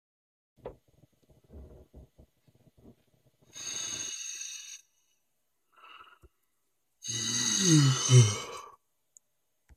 Звуки зевоты
Звук зіхання молодого хлопця